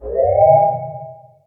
accelerator.ogg